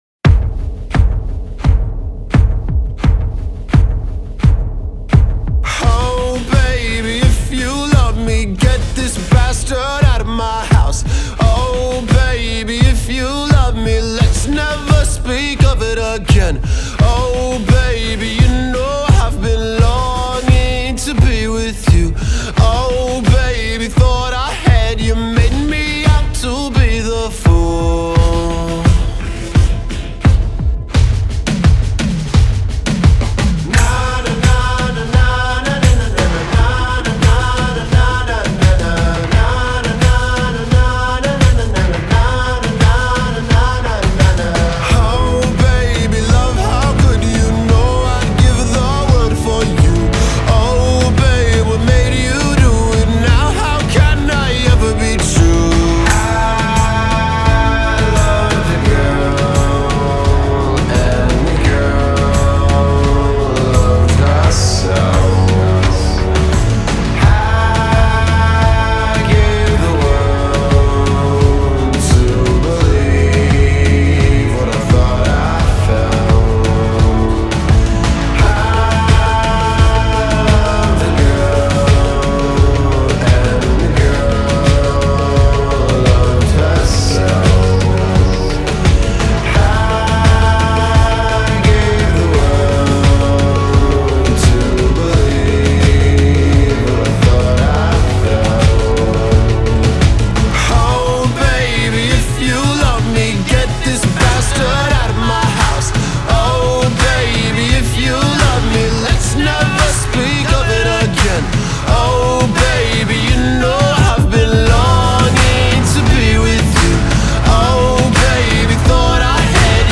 indie pop, w/ graceful nuance
It even, I think, features, albeit briefly, some Auto-Tune.